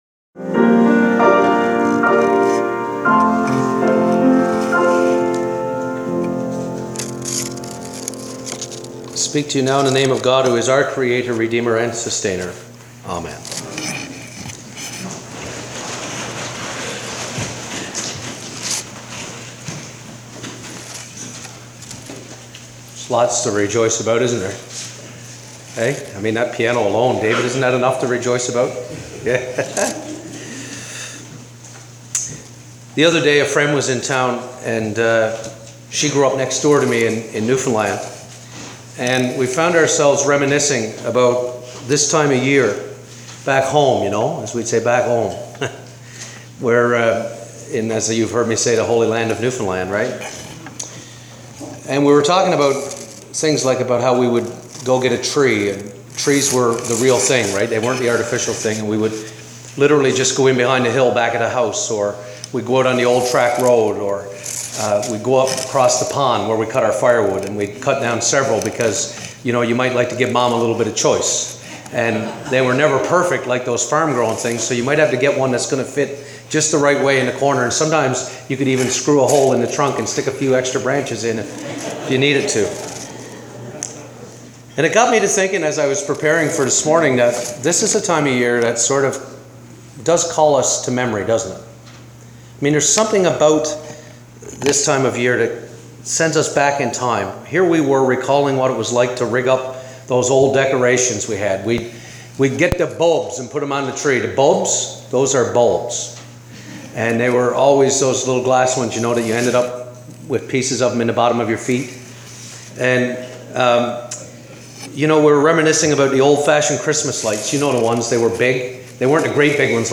Today is the Third Sunday of Advent. Today’s Sermon is focused on the Magnificat.